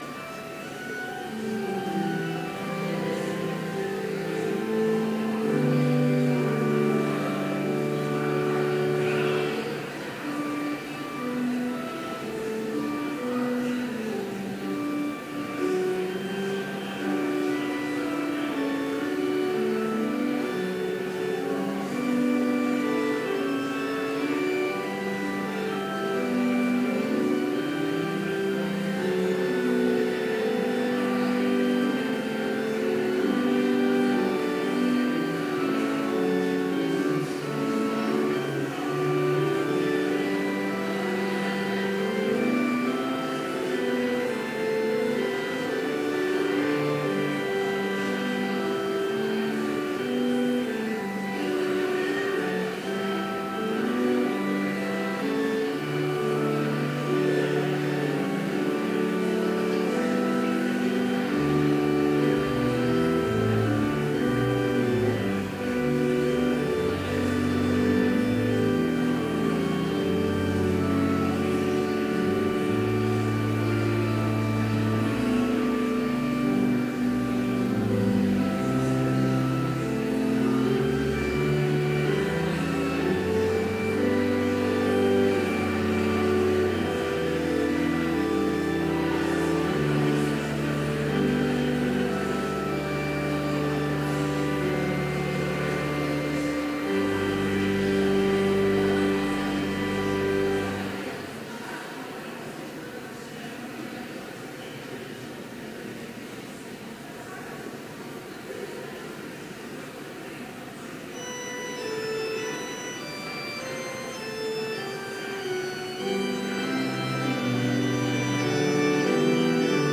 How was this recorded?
Chapel service held on August 25, 2016, in Trinity Chapel